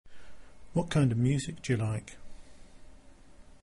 • This means that they are said faster and at a lower volume than stressed syllables, and the vowel sounds lose their purity, often becoming a schwa.
The first is with every word stressed and the second is faster and more natural with vowels being reduced.
schwa_example_2.mp3